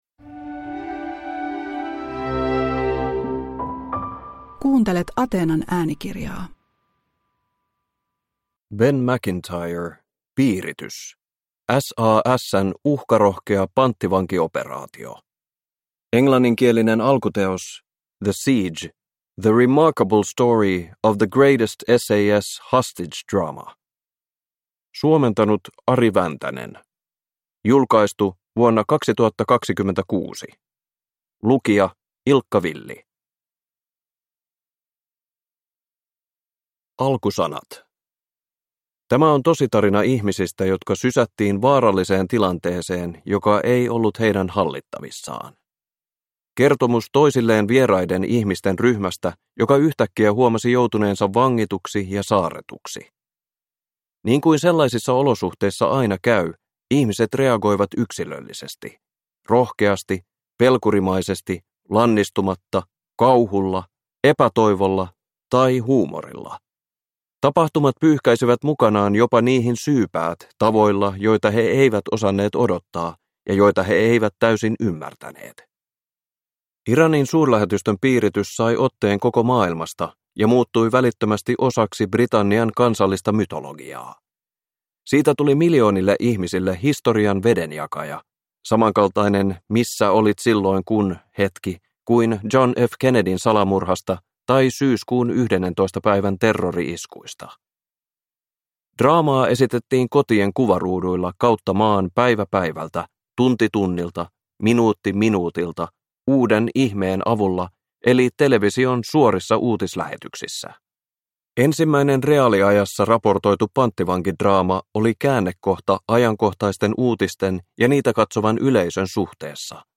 Piiritys – Ljudbok
Uppläsare: Ilkka Villi